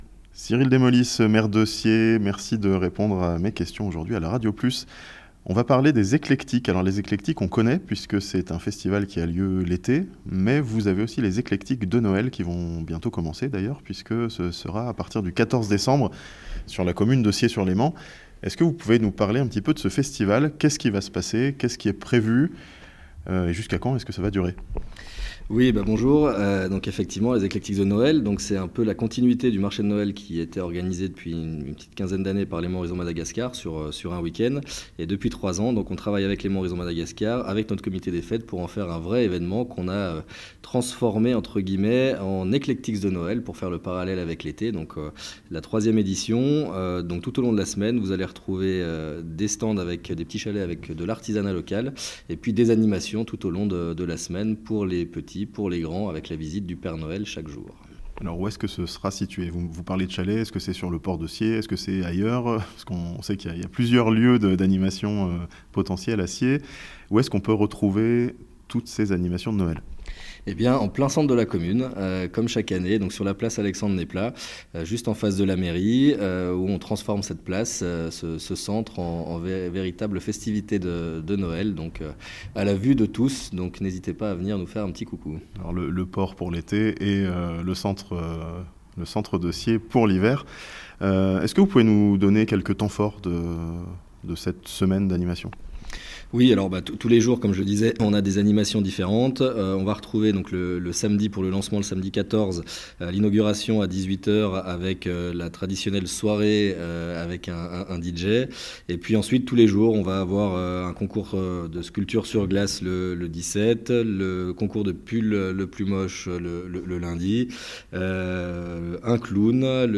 A Sciez-sur-Léman, le festival des Eclectiks se décline aussi en version "Noël" (interview)